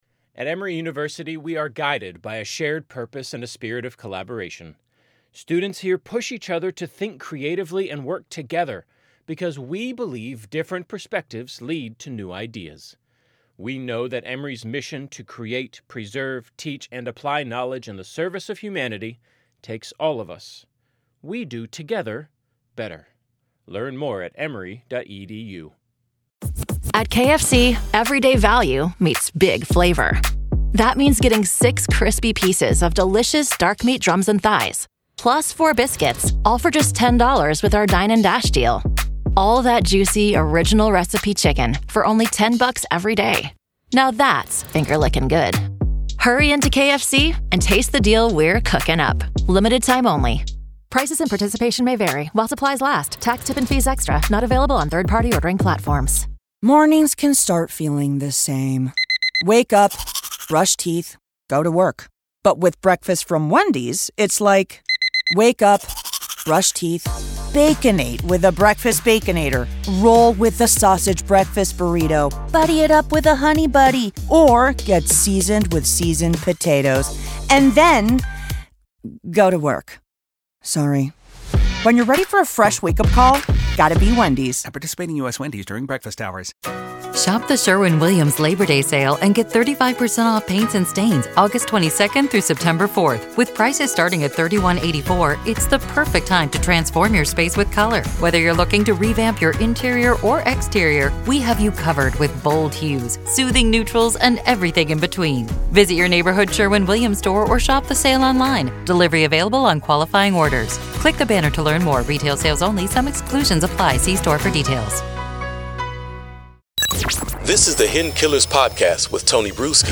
Defense Attorney